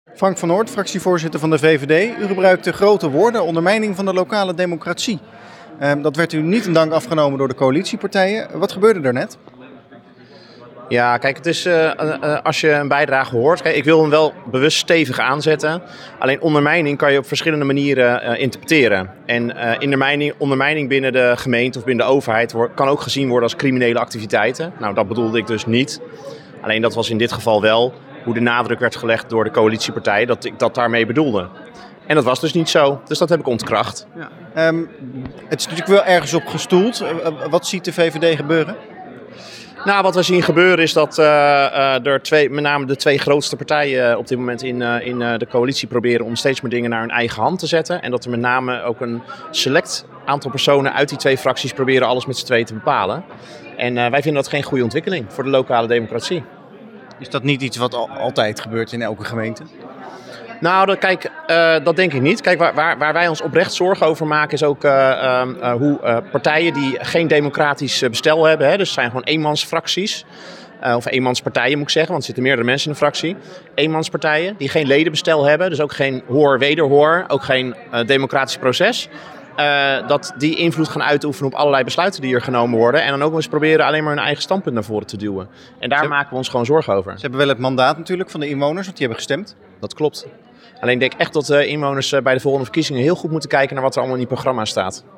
Tijdens het begrotingsdebat vrijdagmiddag ontstond een scherpe discussie tussen VVD-fractievoorzitter Frank van Noort en Hugo Langenberg van de Lokale Partij Leiderdorp (LPL).